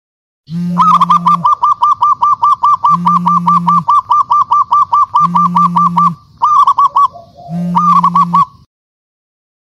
Genre: Nada dering binatang